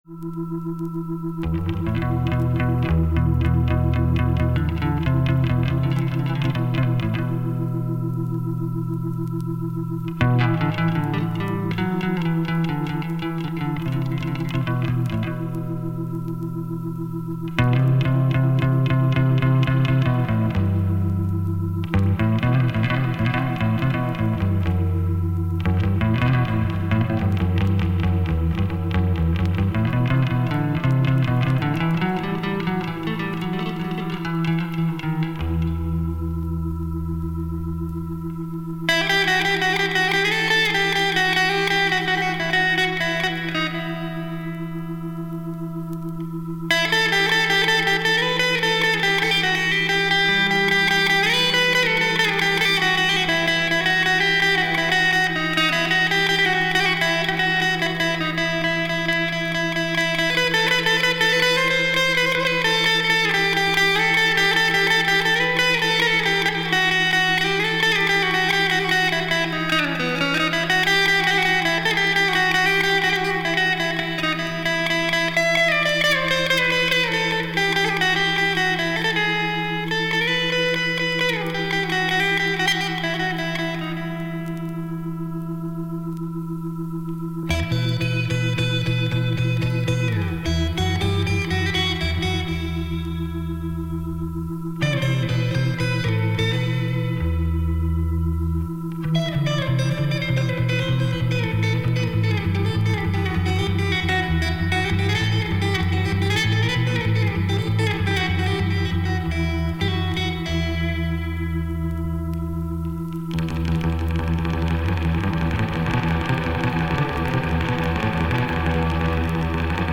Sleeve is nice, record has surface marks, plays great.